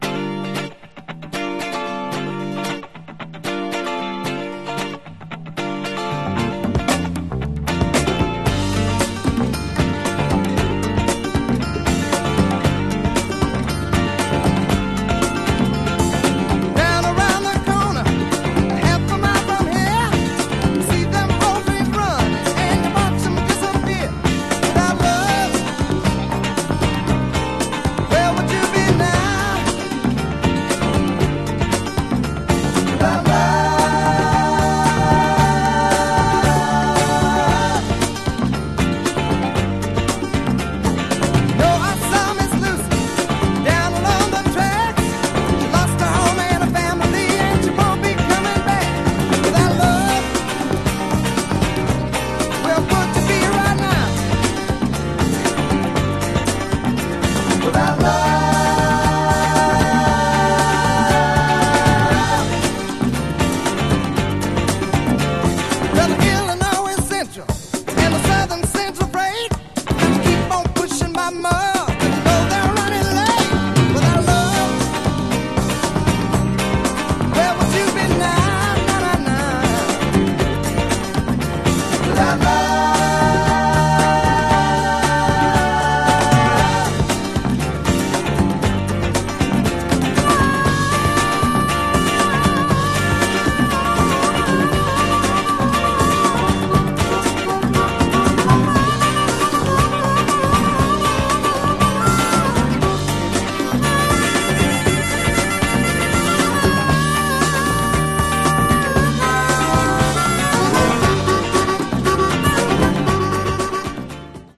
Genre: Country Rock